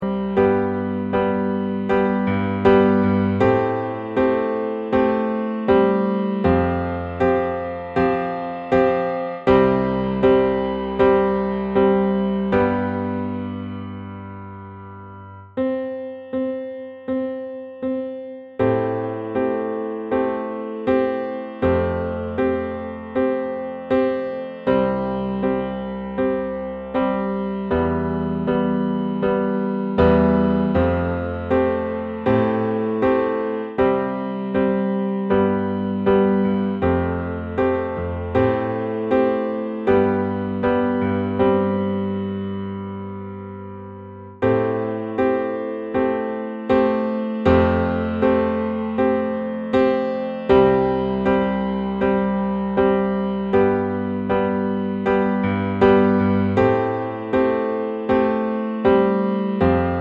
Live Acoustic One Take Pop (2020s) 3:11 Buy £1.50